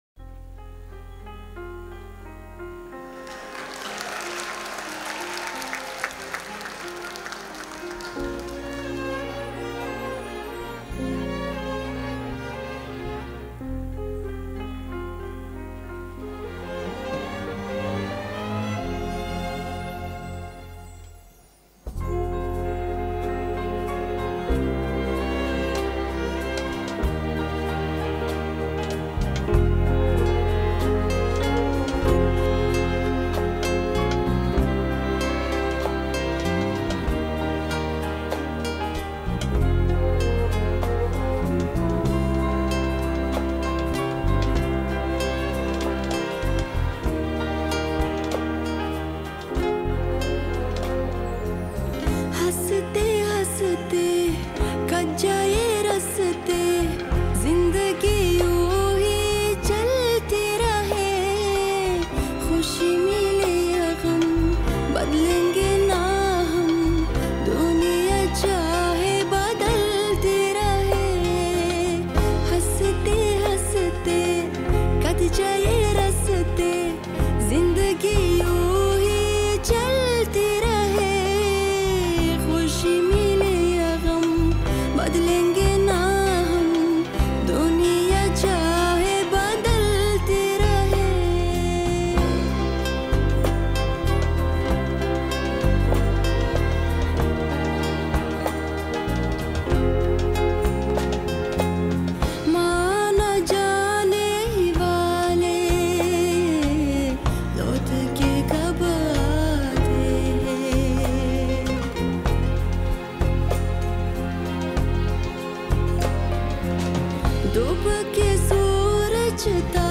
• Категория: Таджикские песни